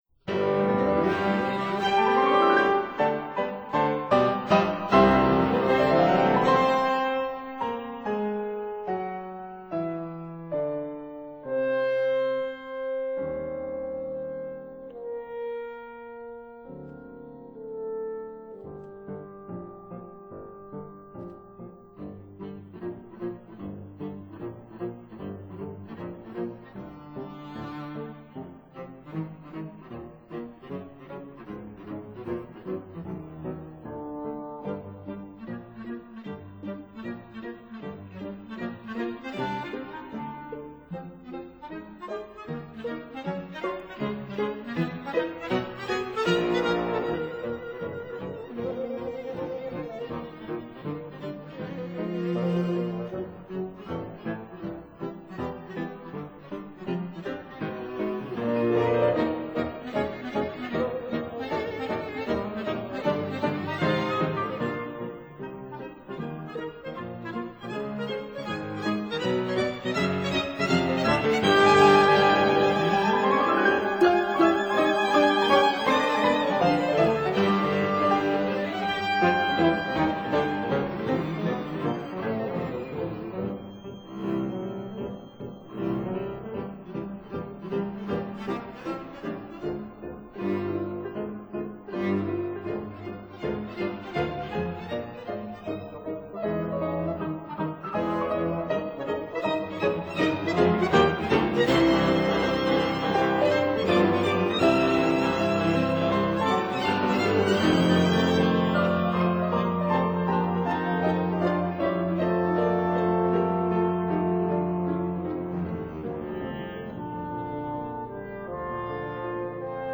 clarinet
bassoon
horn
viola
piano